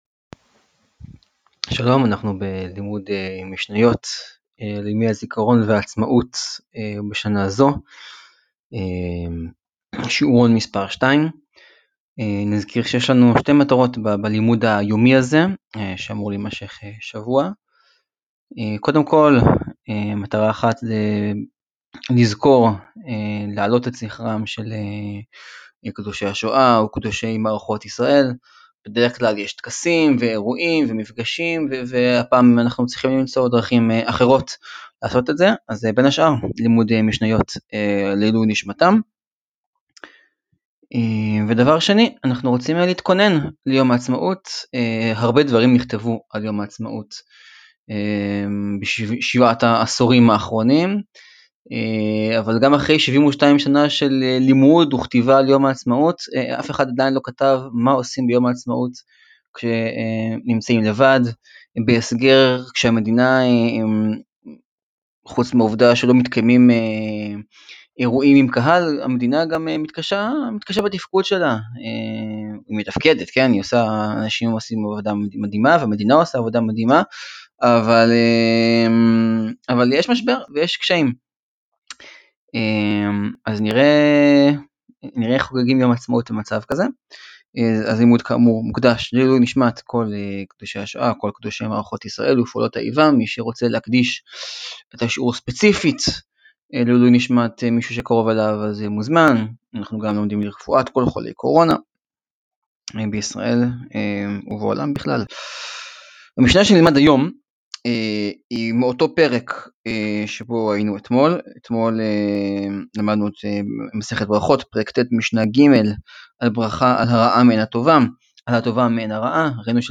לימוד יומי בשאלה הנשאלת לראשונה מזה 72 שנה: האם יש משמעות לחגיגת עצמאות לבד בבית? שיעורון מספר 2: הללויה על מה שהיה לימוד המשניות מוקדש לעילוי נשמת קדושי השואה וחללי מערכות ישראל ופעולות האיבה, ולרפואת החולים בנגיף קורונה.